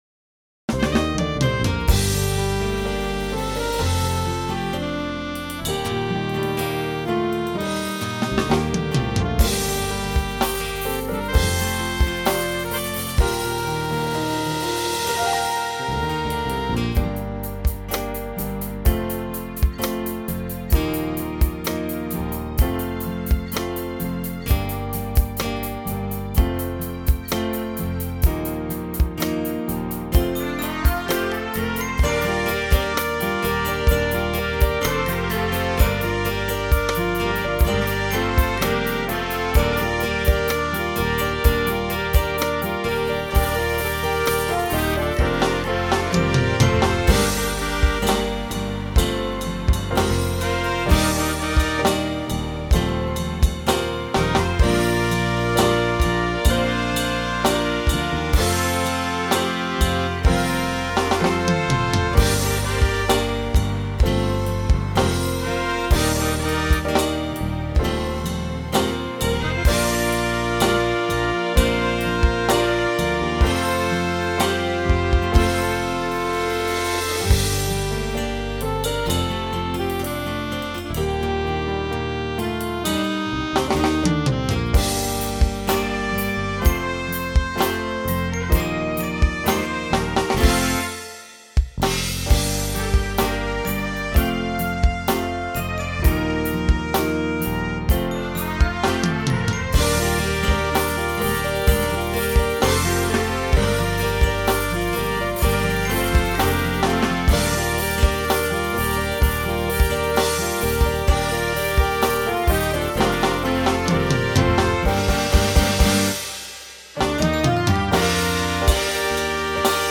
SATB Instrumental combo
Country
Ballad